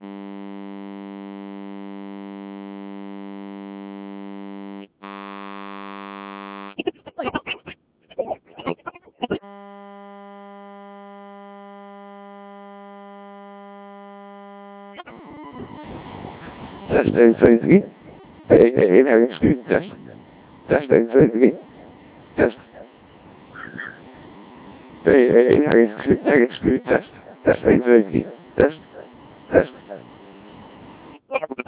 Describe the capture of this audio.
Recording of the received audio at the repeater can be found here: (The signal was fine, the audio going into the encoder was terrible, just a simple electret mic connected to the atsame70 cpu)